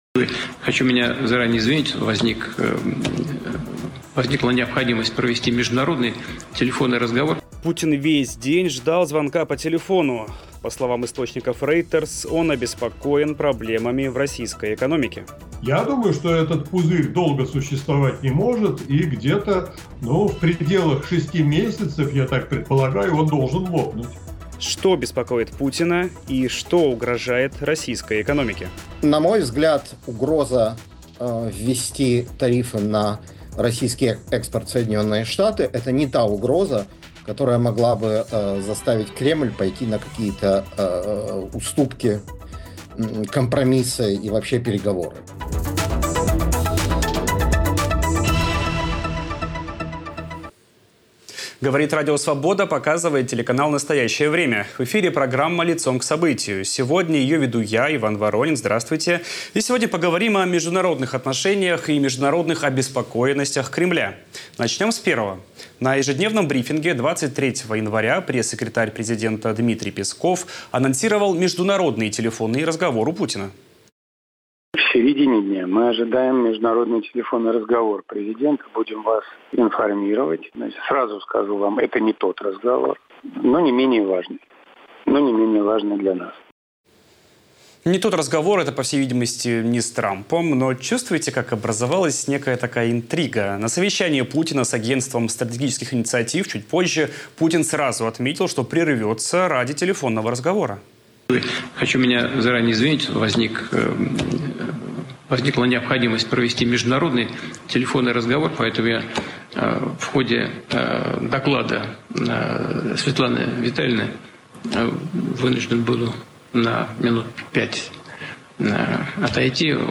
В ежедневном режиме анализируем главные события дня. Все детали в прямом эфире, всегда Лицом к Событию
Все детали в прямом эфире, всегда Лицом к Событию … continue reading 124 episodios # Радио Свобода # Обсуждение Новостей # Новости